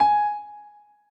b_piano2_v100l16-3o6gp.ogg